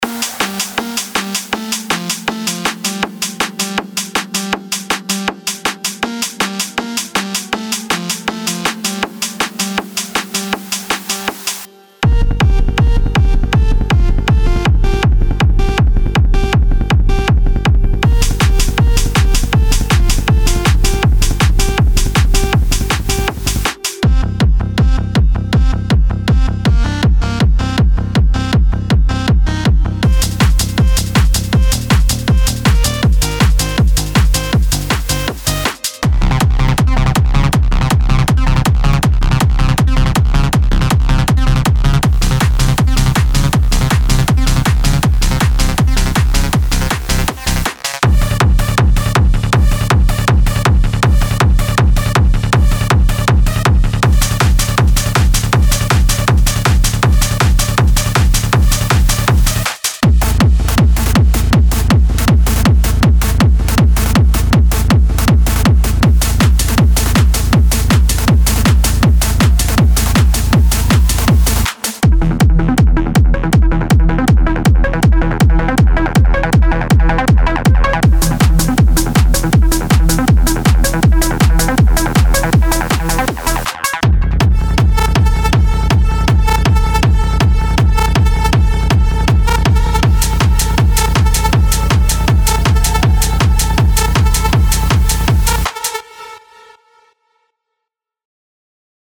Genre:Techno
内部では、すべてのループがフル稼働する機械のような衝撃を放ちます。
これは、深夜の倉庫、煙に包まれたストロボ、そして純粋な力に突き動かされる身体のサウンドです。
デモサウンドはコチラ↓
20 Bass Loops
20 Drum Loops
20 Synth Loops
20 FX Loops